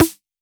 Index of /musicradar/retro-drum-machine-samples/Drums Hits/WEM Copicat
RDM_Copicat_MT40-Snr02.wav